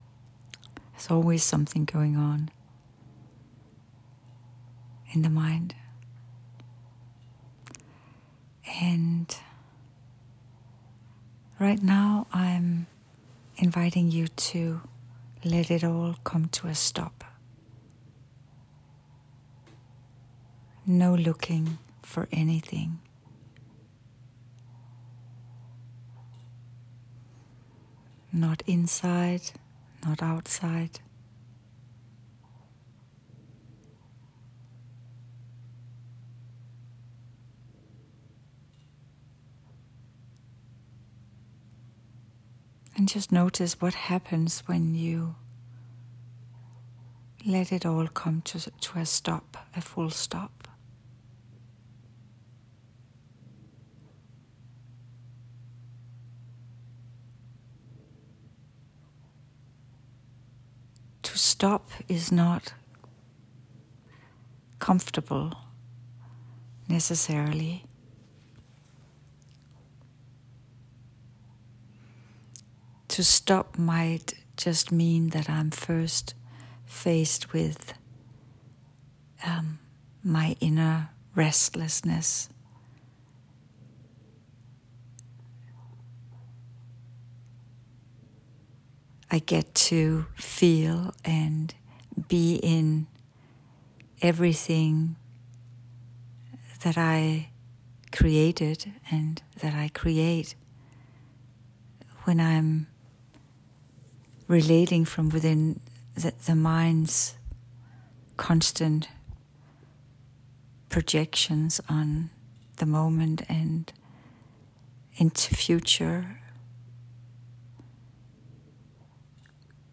This is an unprepared transmission, spoken to you as we are together. As we move beyond inner fixed structures to connect with deeper levels of stillness, movement and presence grounded in pure being.